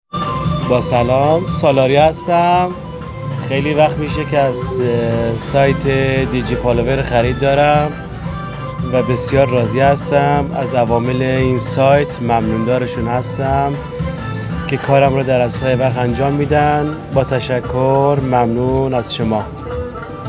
ویس مشتریان عزیزمان با صدای خودشان در مورد سایت دیجی فالوور